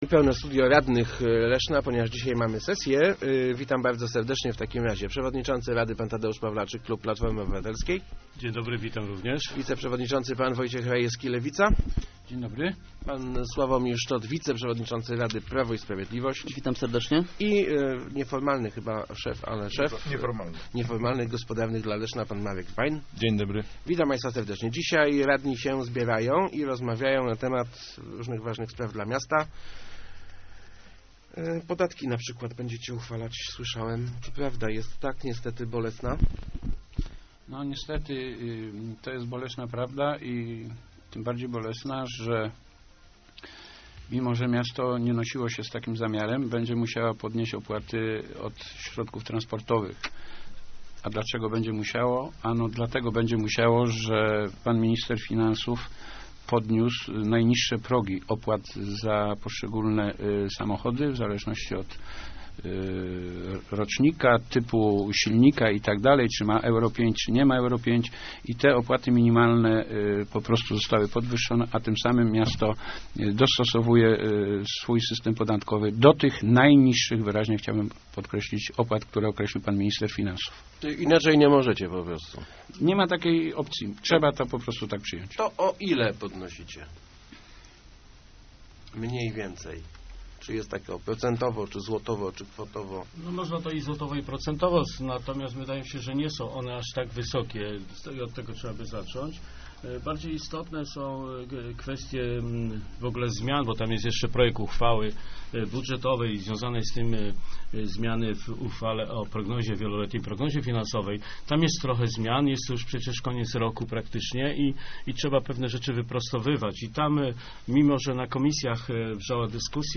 Jak zaoszczędzić na rachunkach? - zdaniem klubu PiS miasto powinno wejść w skład "grupy zakupowej", która będzie mogła nogocjować ceny energii. Radni innych klubów przyznali w Rozmowach Elki, że to dobry pomysł.